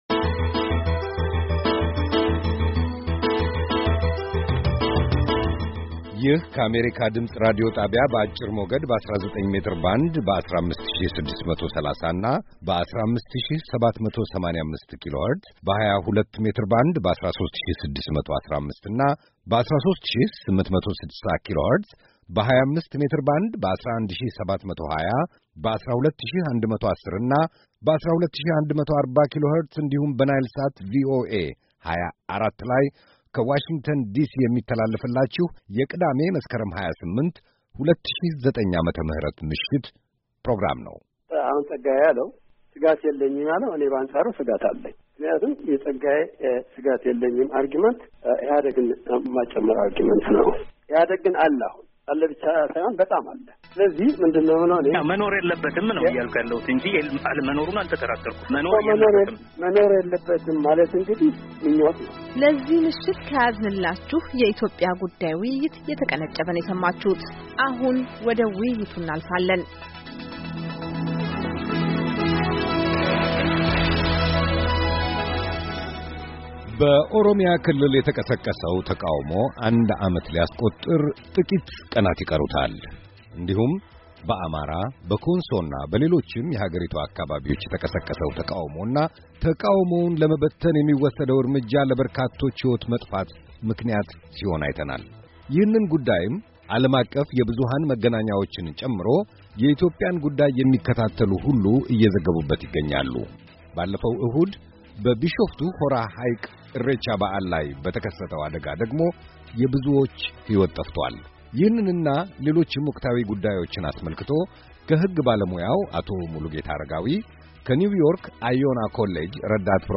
ውይይት ከሦስት በኢትዮጵያ ወቅታዊ ጉዳይ (ክፍል ሁለትና የመጨረሻ)